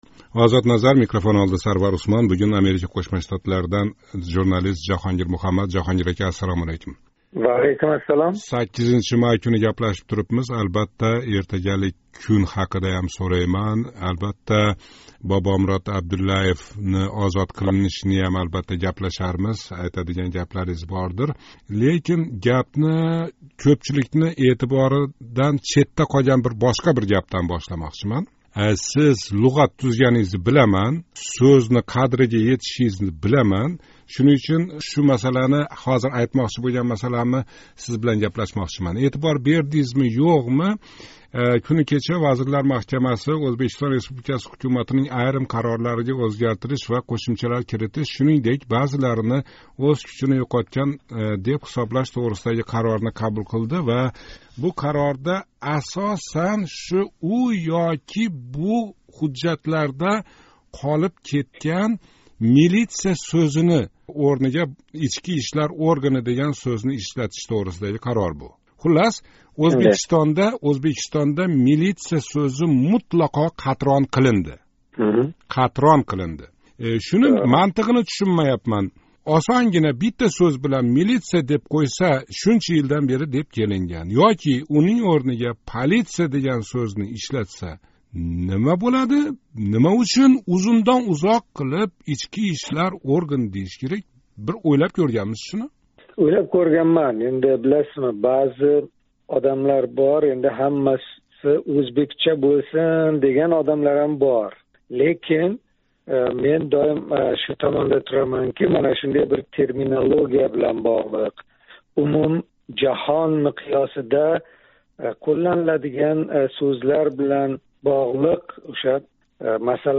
суҳбатда